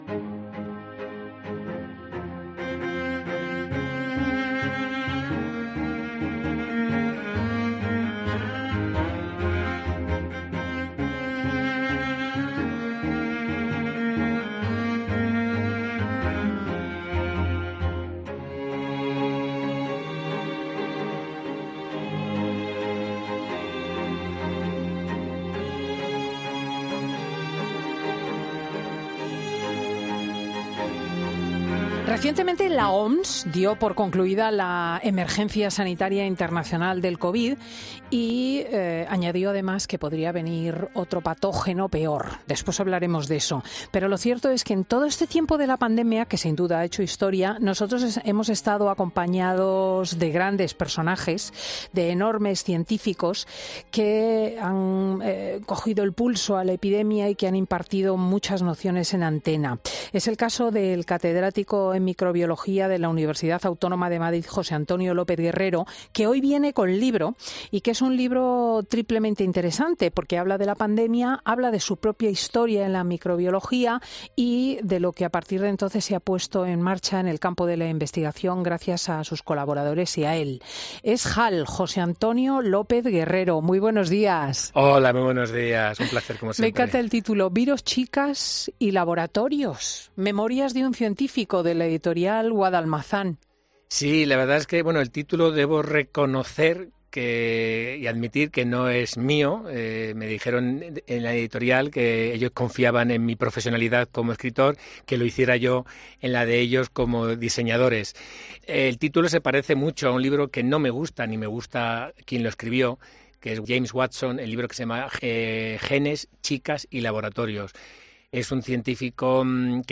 Y muchas de esas clases magistrales, fueron impartidas en antena por el catedrático en microbiología de la Universidad Autónoma de Madrid, José Antonio López Guerrero que presenta en Fin de Semana su último libro: 'Virus, chicas y laboratorios' que edita 'Guadalmazán'.
"Fin de Semana" es un programa presentado por Cristina López Schlichting , prestigiosa comunicadora de radio y articulista en prensa, es un magazine que se emite en COPE , los sábados y domingos, de 10.00 a 14.00 horas.